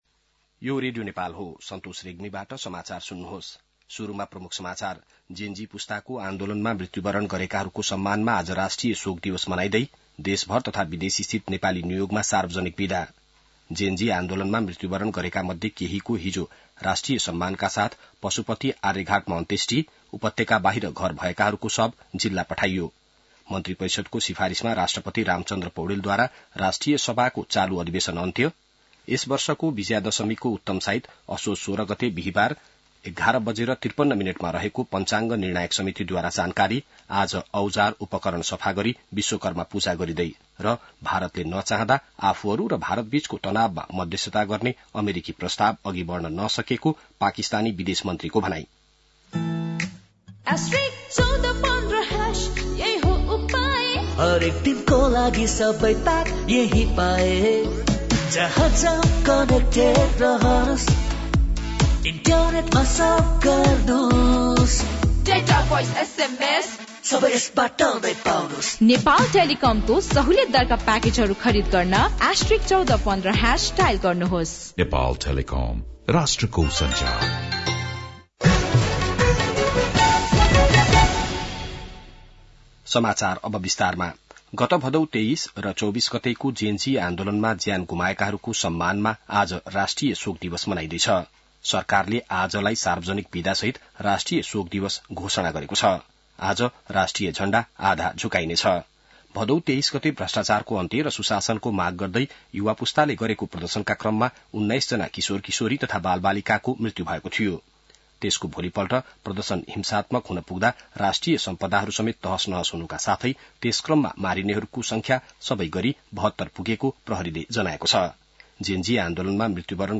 बिहान ७ बजेको नेपाली समाचार : १ असोज , २०८२